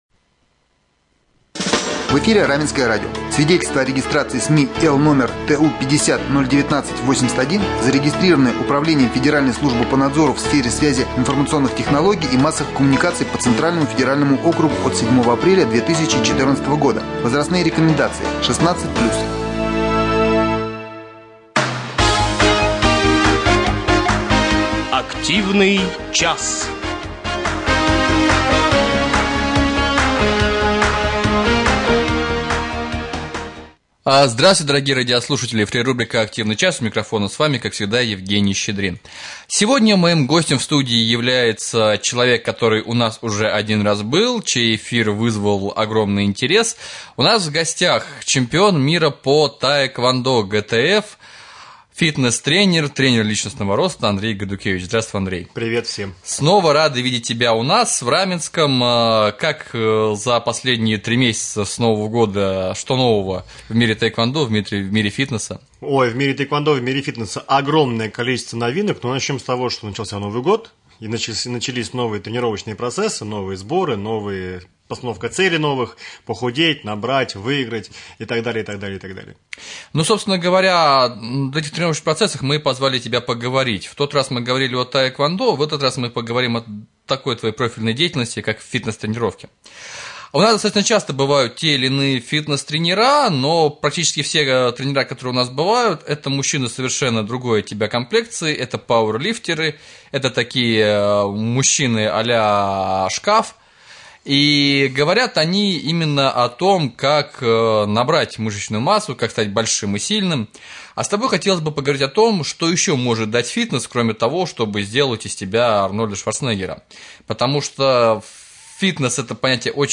Как выбрать фитнес тренера? Как поставить цель тренировок? Что нужно купить для спортзала? В гостях у Раменского радио, в рубрике "Активный час" фитнес